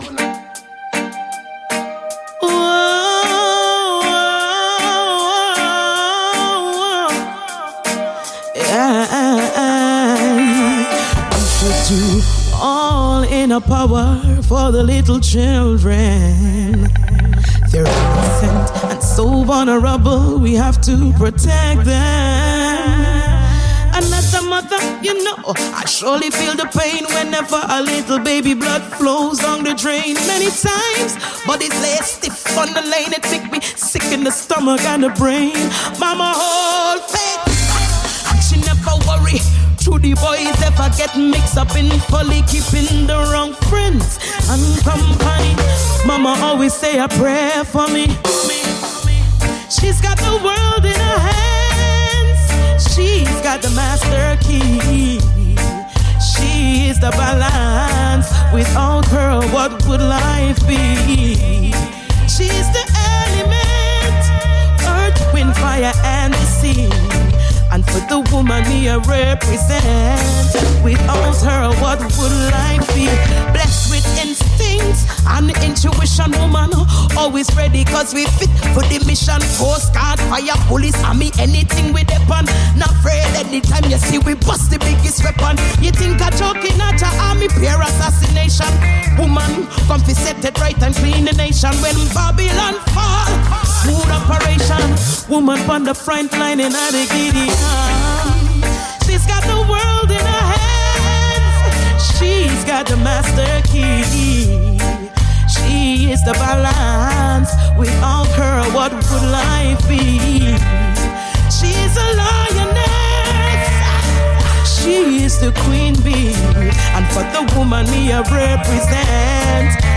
Artista-a-la-Vista-Intervista-Queen-Omega.mp3